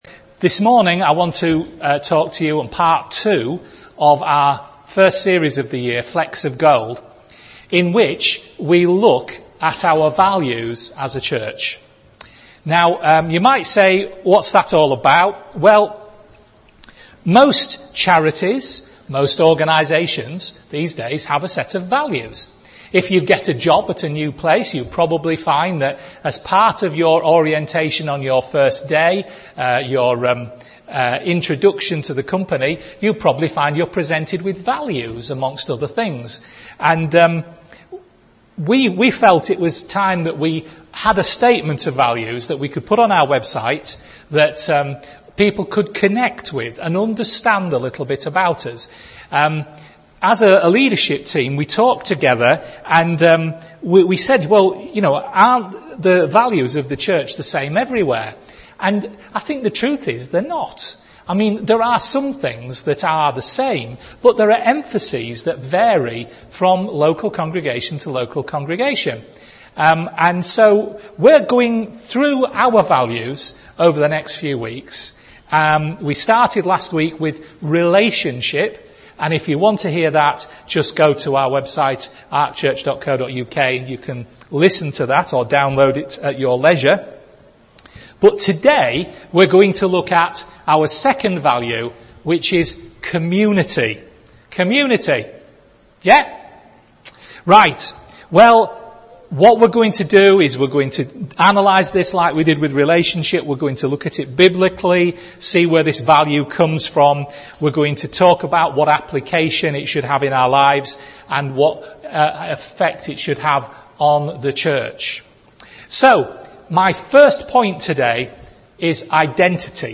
A preaching series that examines our core values as a church, ask ourselves why we hold them so precious, talk about their biblical basis and also what application we should see in our lives and in this church as we implement them. Today we discuss 'community'